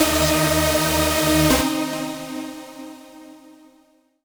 VEC3 FX Athmosphere 19.wav